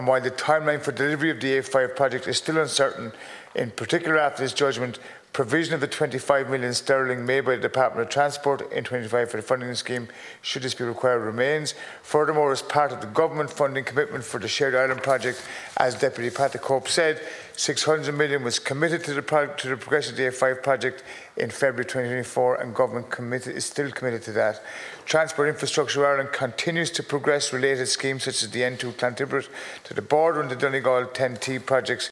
The issue was raised in the Dail last night by Deputy Pat the Cope Gallagher.